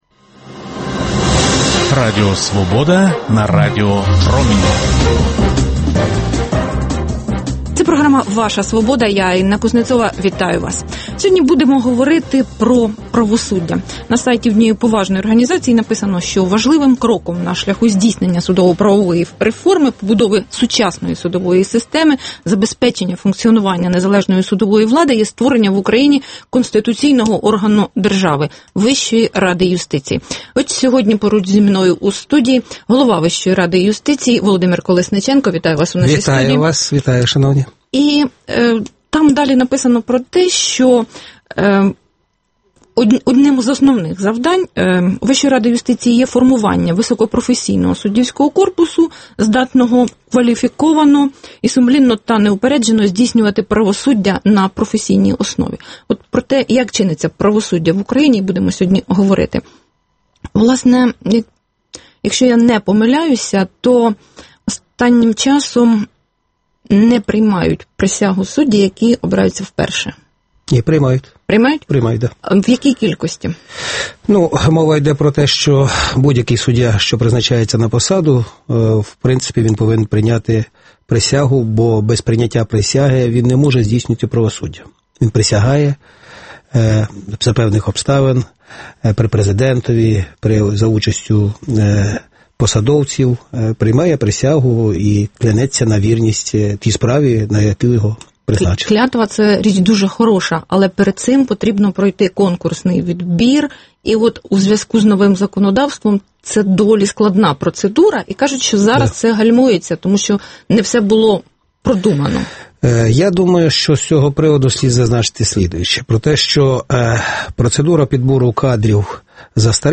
Дискусія про головну подію дня